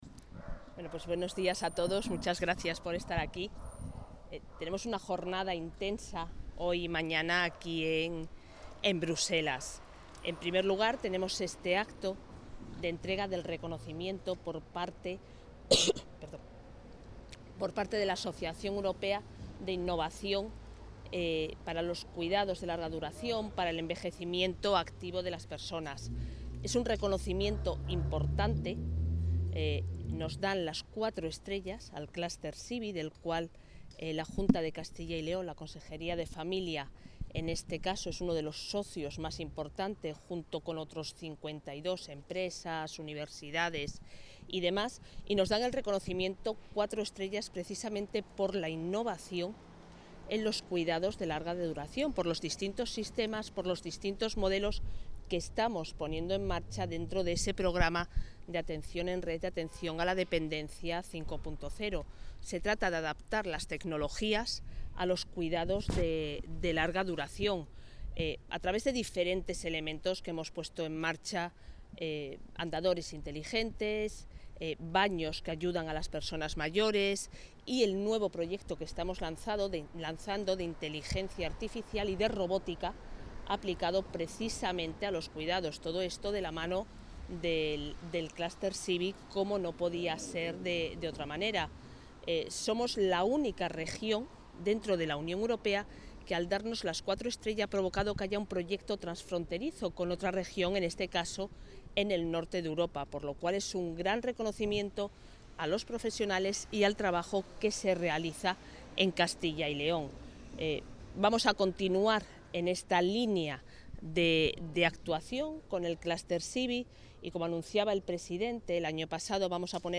Intervención de la consejera.